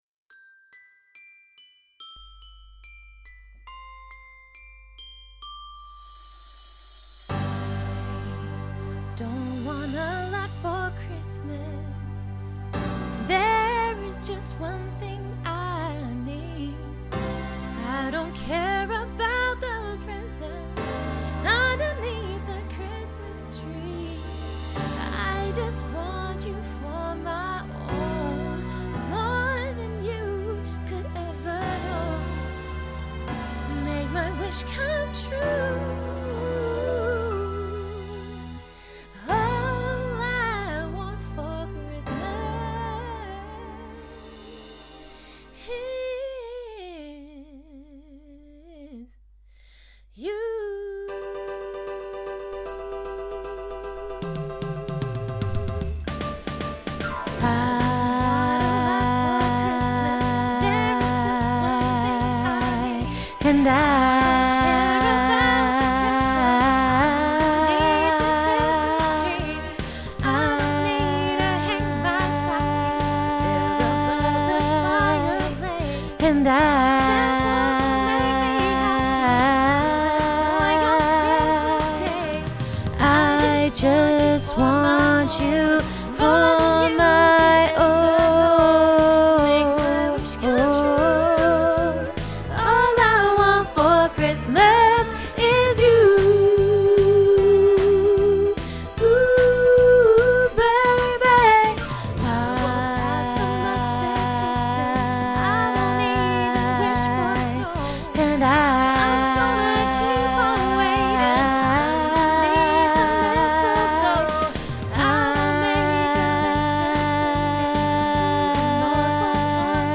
Christmas Choir